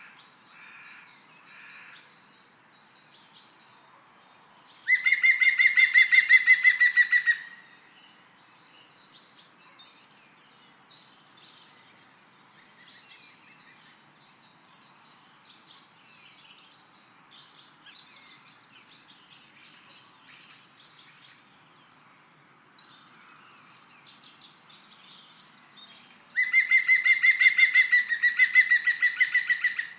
Pic vert - Mes zoazos
Son rire caractéristique résonne fréquemment dans les bois et les parcs.
pic-vert.mp3